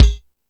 Lotsa Kicks(45).wav